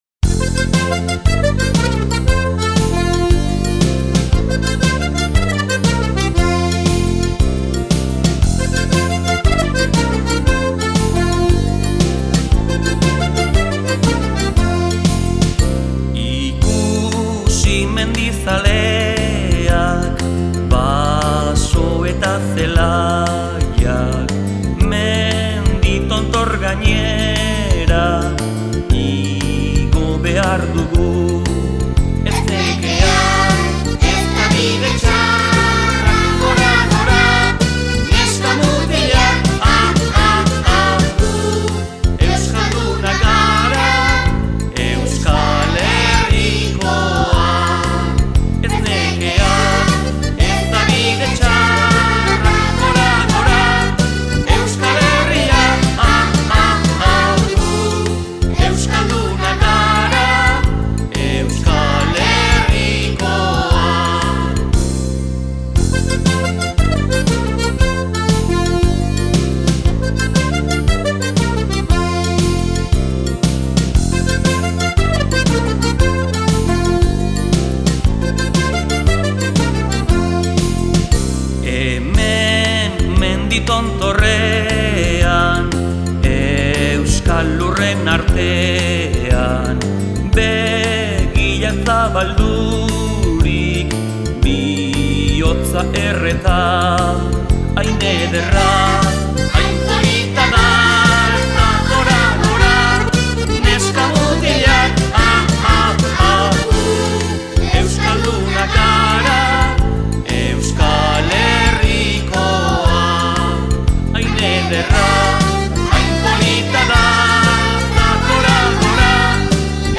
This series of Basque songs for kids was compiled by Basque newspaper EGUNKARIA.